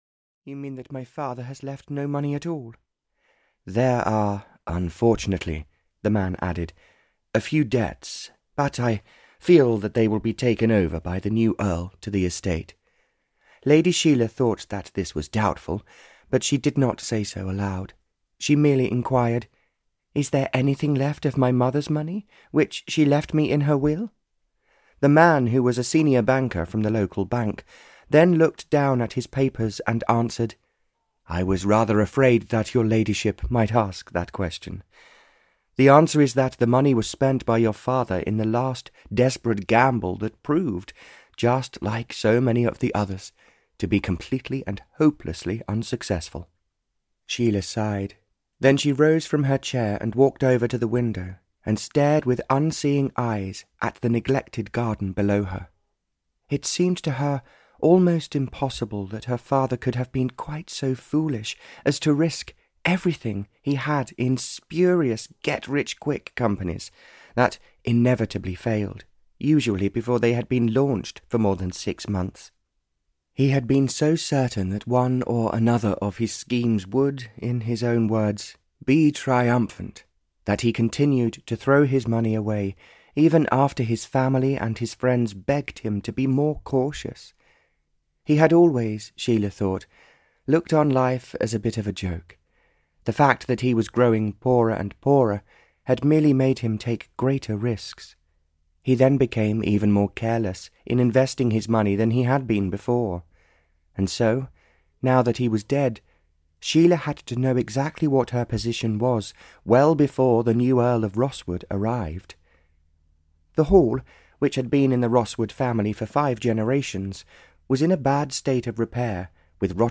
Love Solves the Problem (Barbara Cartland’s Pink Collection 120) (EN) audiokniha
Ukázka z knihy